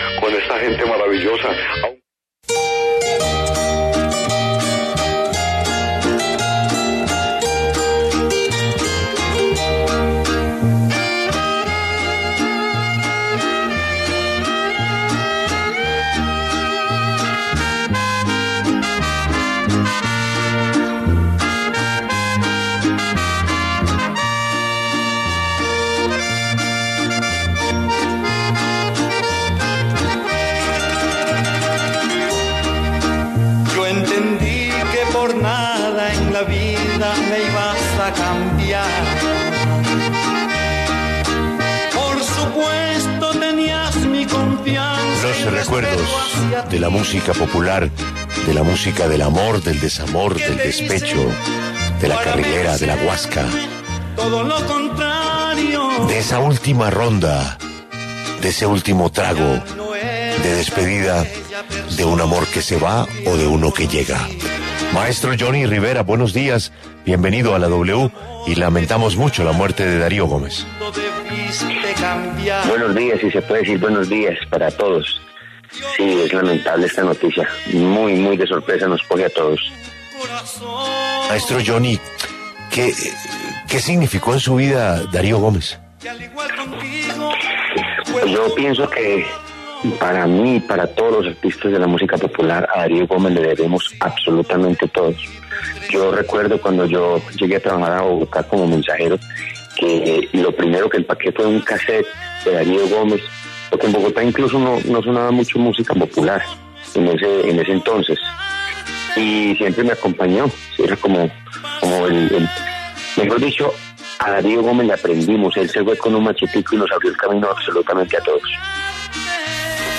Escuche la entrevista completa a Jhonny Rivera en La W: